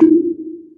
MagicCity Perc 5.wav